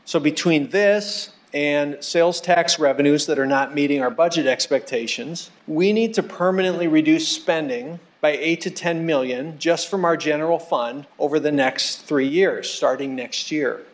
Mayor Paul Tenhaken delivered his final budget address Thursday.